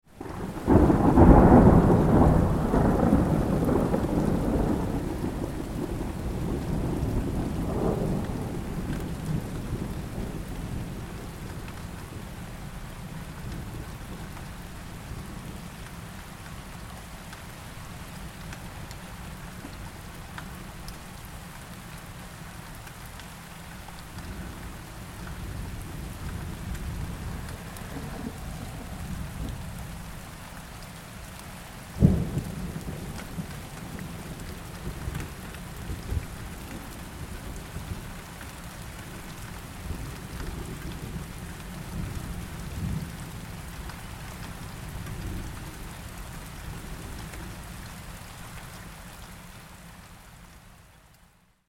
دانلود آهنگ طوفان 13 از افکت صوتی طبیعت و محیط
جلوه های صوتی
دانلود صدای طوفان 13 از ساعد نیوز با لینک مستقیم و کیفیت بالا